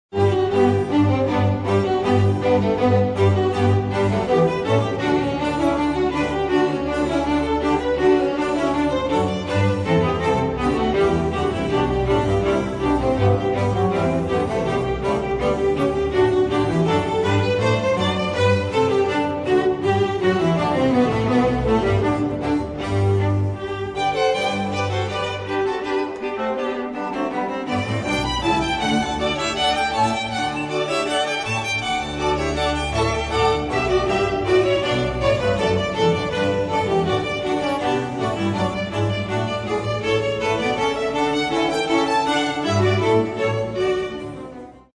* The Brandenburg concertos (BWV 1046–1051) are a collection of six instrumental works created by J.S. Bach.
They are widely regarded as among the finest musical compositions of the Baroque era.